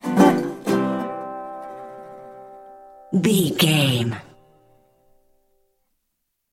Ionian/Major
acoustic guitar
percussion
ukulele
slack key guitar